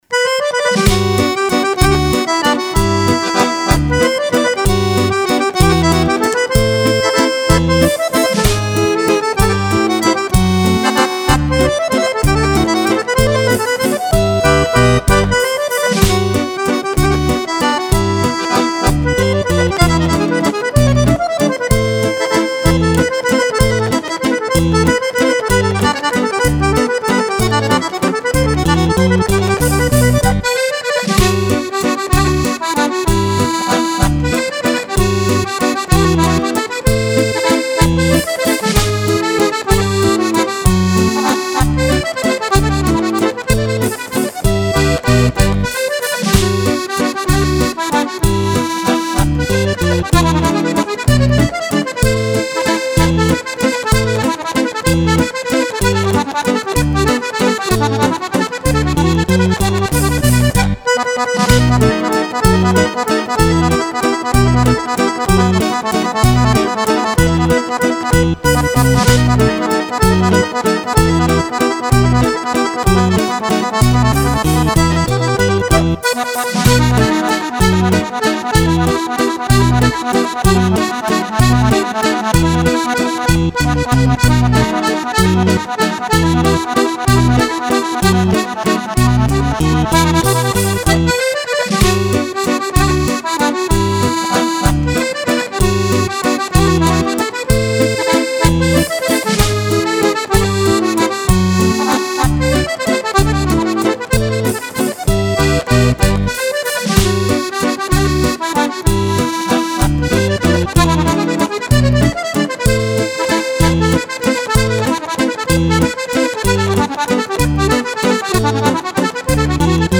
Valzer Musette
Due ballabili per Fisarmonica